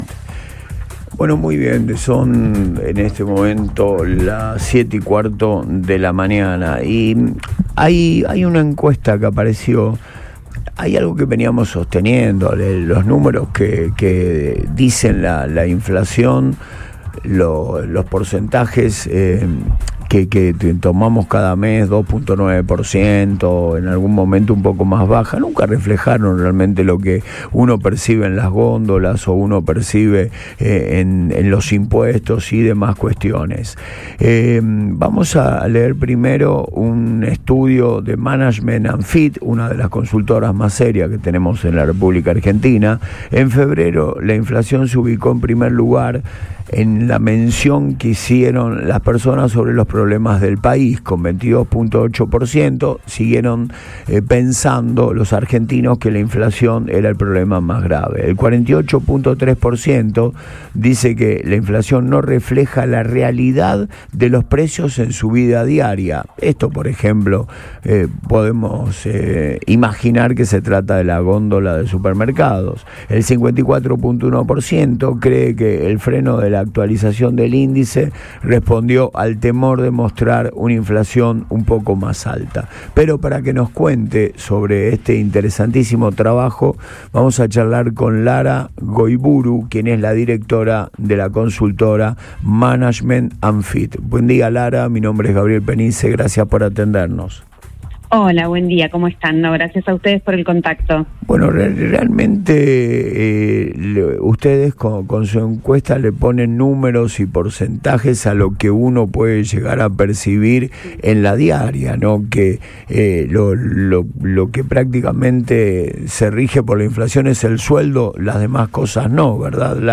analizó en Antes de Todo por Radio Boing el último relevamiento nacional que pone números a la crisis económica.